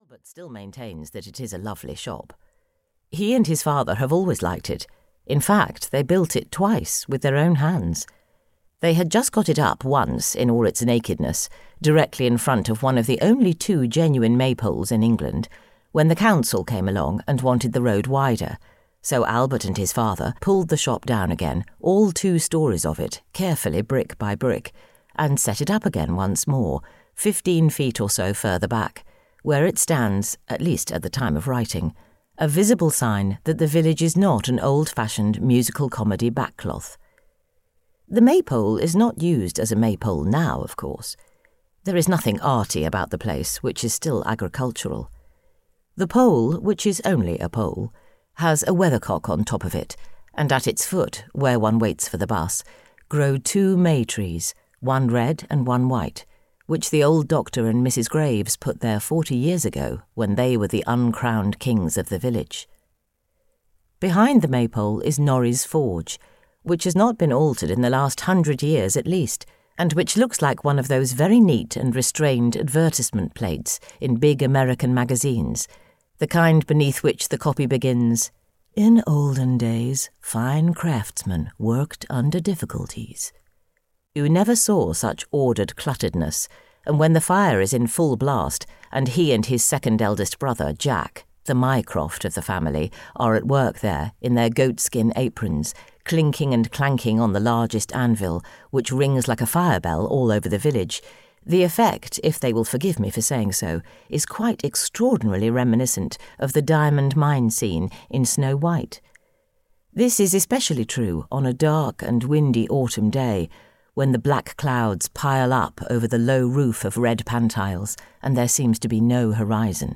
The Oaken Heart (EN) audiokniha
Ukázka z knihy